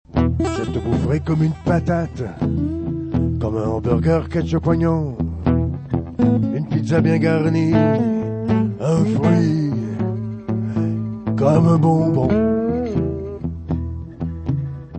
chanson influences diverses